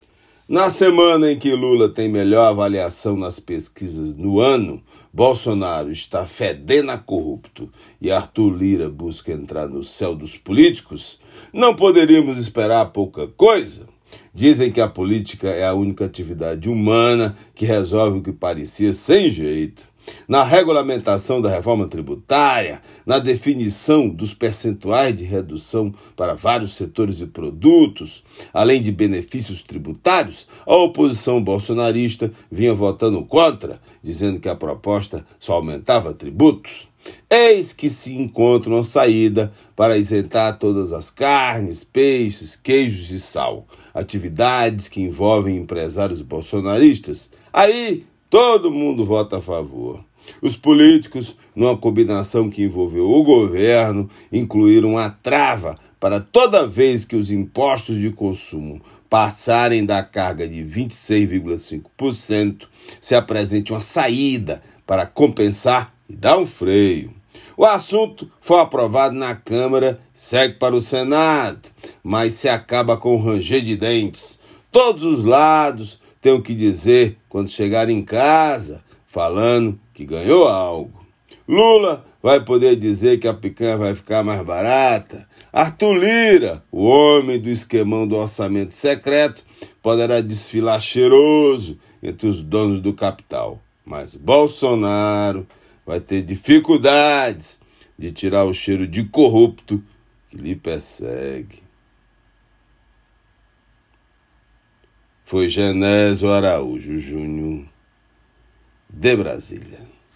Comentário desta quinta-feira
direto de Brasília.